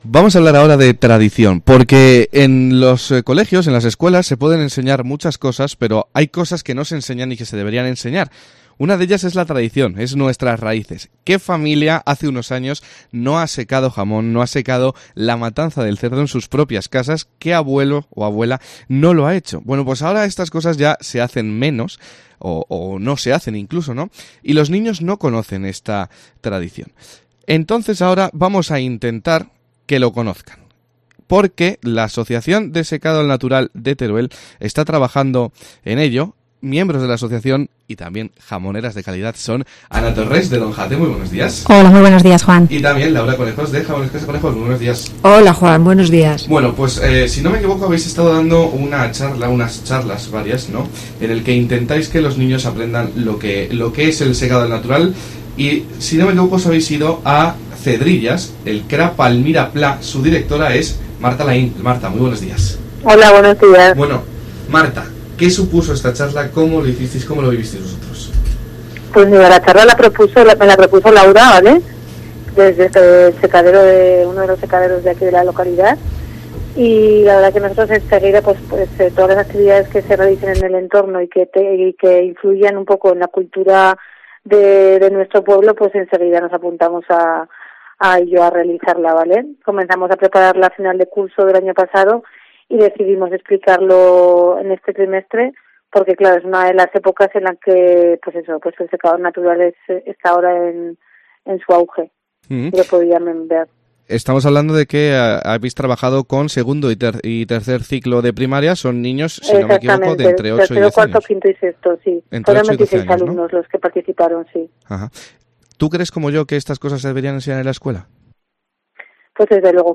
Toda la explicación, estuvo a cargo de dos mujeres emprendedoras, que directamente han hecho del secado natural de carnes y embutidos su medio de vida.